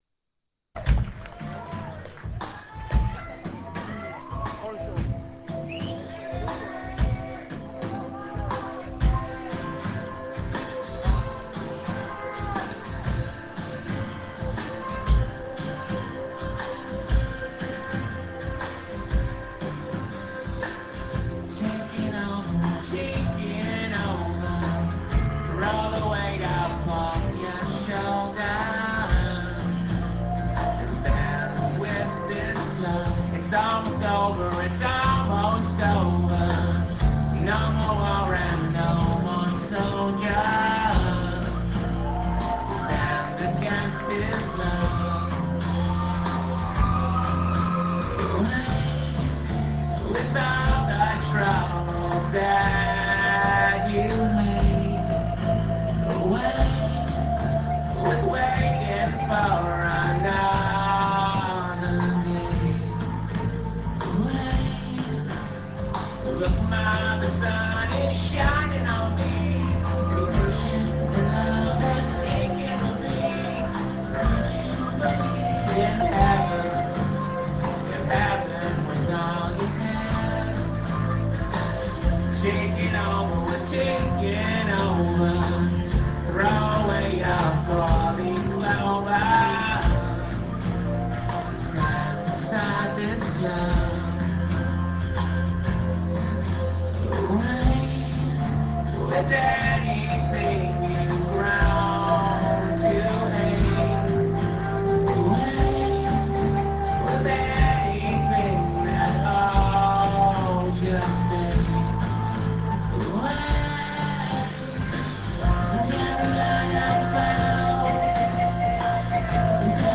St. Andrew's Hall; Detroit, USA
acoustique. on line